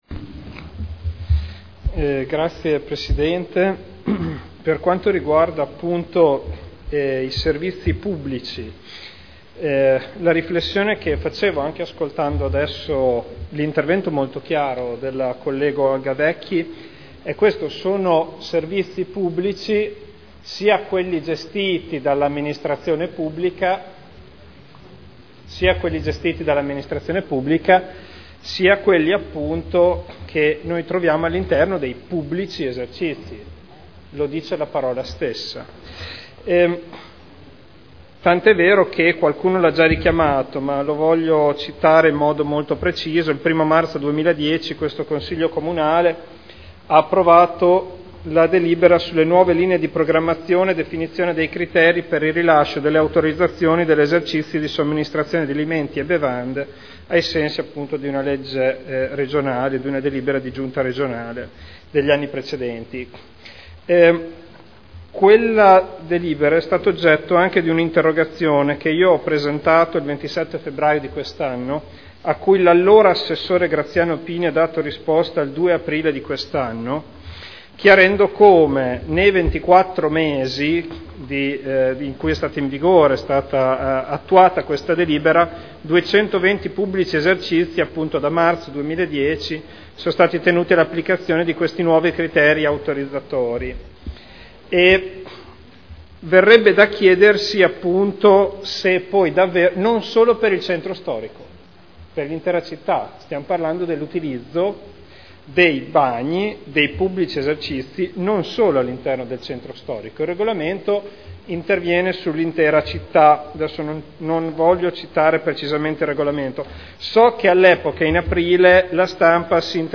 Federico Ricci — Sito Audio Consiglio Comunale
Seduta del 10/09/2012 dibattito su Interrogazione del consigliere Bellei (PdL) avente per oggetto: “Assurdi gli orari dei bagni pubblici. Com’è possibile dover pagare per accedervi?” (presentata il 21 maggio 2012 – in trattazione il 10.9.2012) e Interrogazione del consigliere Bellei (PdL) avente per oggetto: “Situazione disastrosa dei gabinetti pubblici, indegna della nostra città” (presentata il 9 luglio 2012 – in trattazione il 10.9.2012)